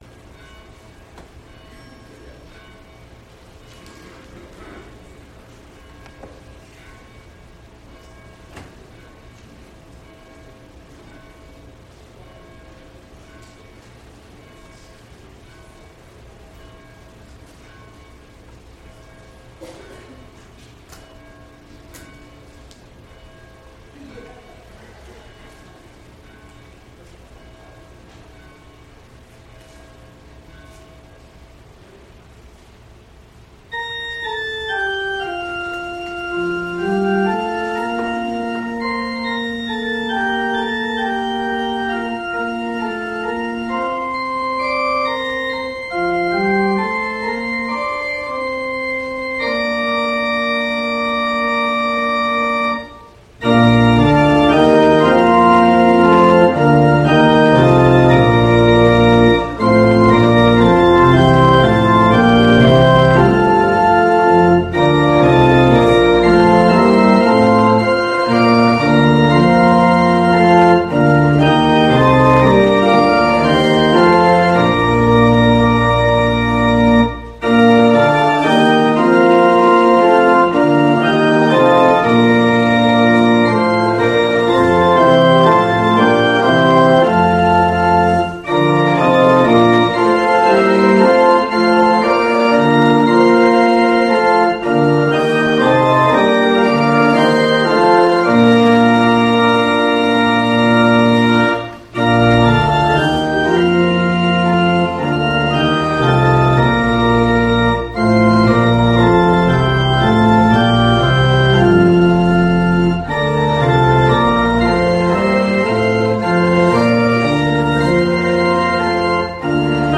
Bethlehem Lutheran Church, Mason City, Iowa - Sermon Archive Jan 26, 2020